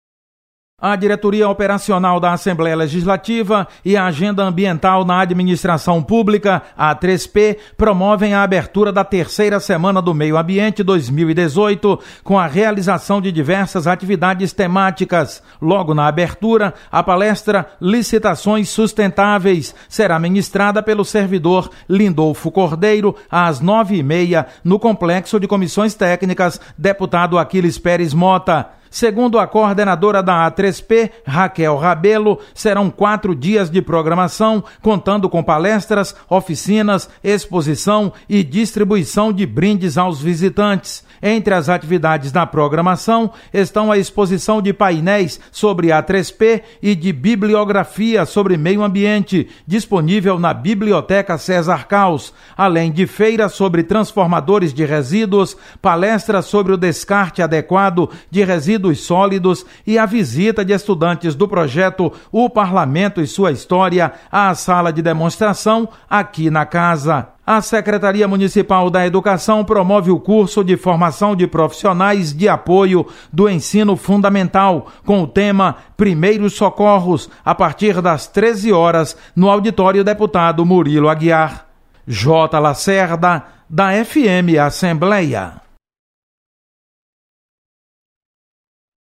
Acompanhe a agenda das atividades da Assembleia Legislativa nesta segunda-feira. Repórter